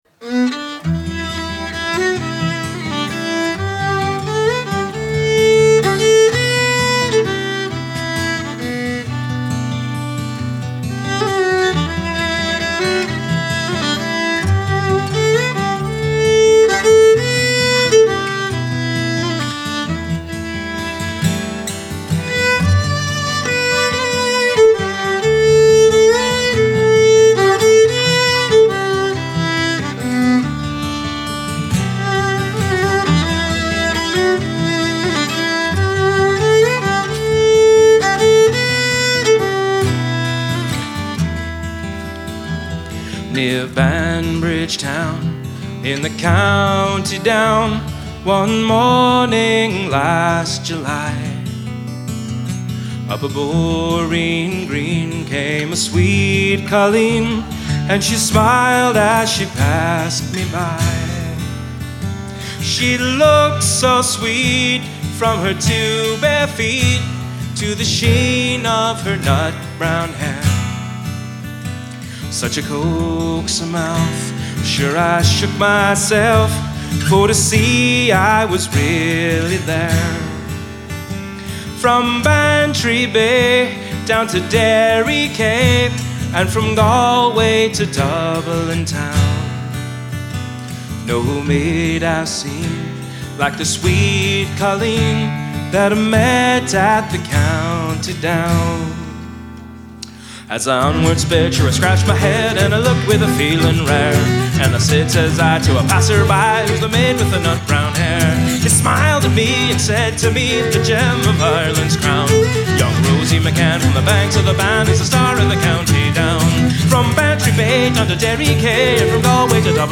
Recorded at The Prom in Bristol.